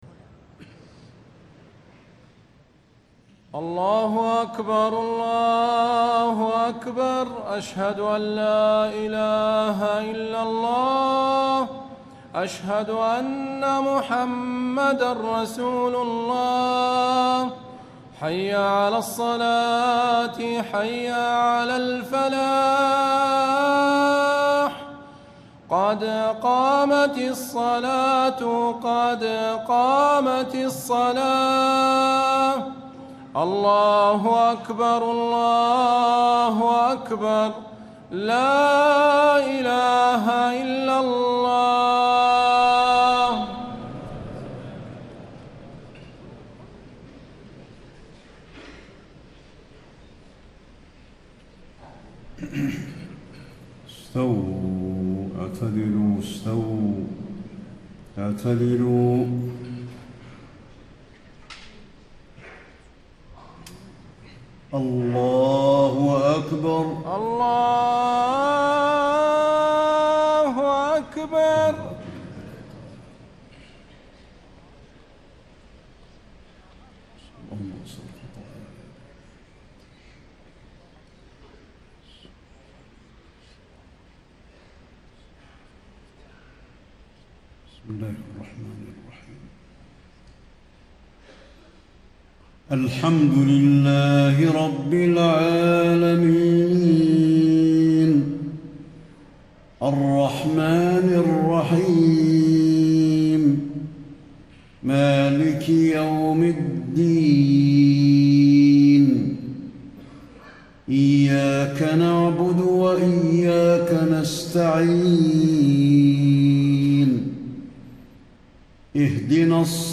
فجر 29 رمضان ١٤٣٥ من سورة الزخرف > 1435 🕌 > الفروض - تلاوات الحرمين